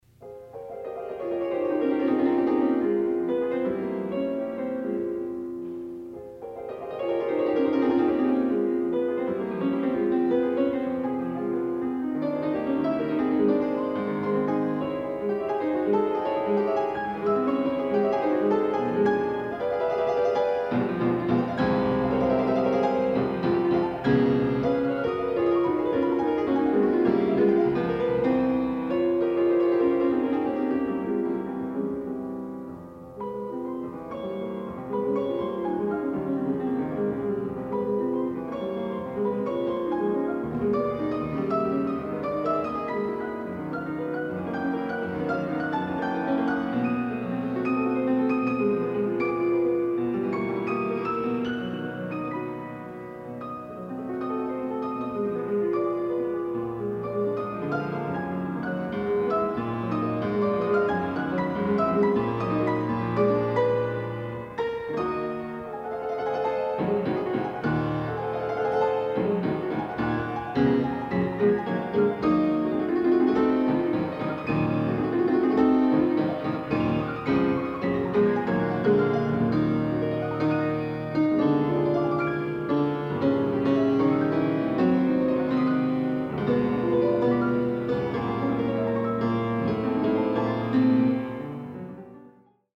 Additional Date(s)Recorded September 12, 1977 in the Ed Landreth Hall, Texas Christian University, Fort Worth, Texas
Etudes
Short audio samples from performance